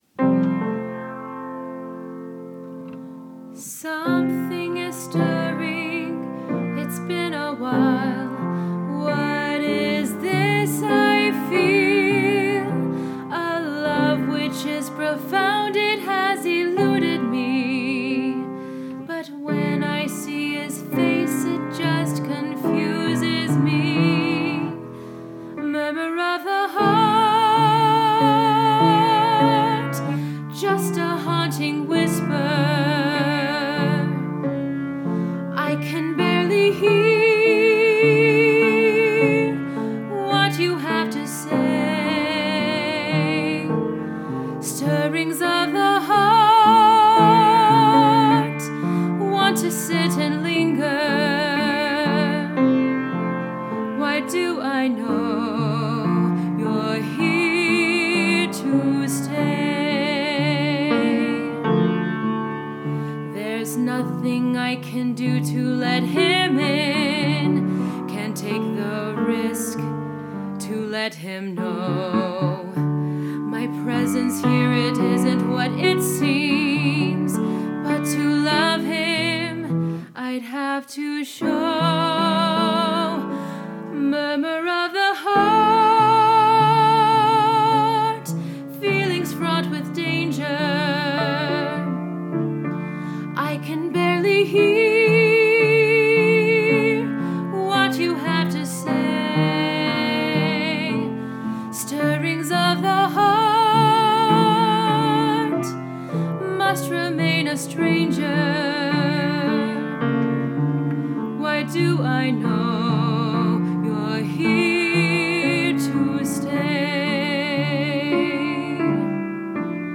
A musical satire for 5 actor/singers and piano